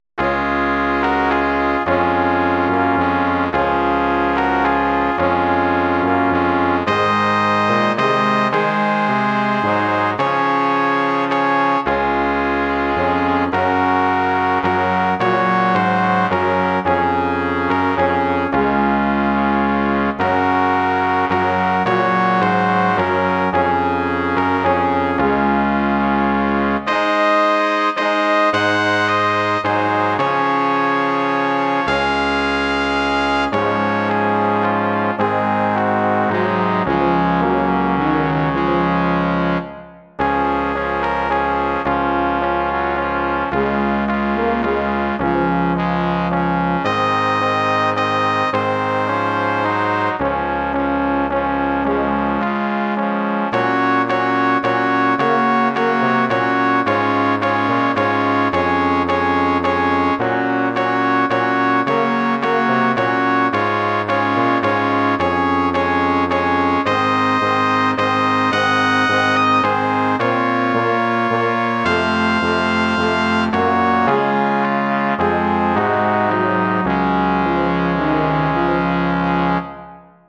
Bearbeitung für Blechbläserquintett
Besetzung: 2 Trompeten, Horn, Posaune, Tuba
arrangement for brass quintet
Instrumentation: 2 trumpets, horn, trombone, tuba